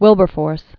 (wĭlbər-fôrs), William 1759-1833.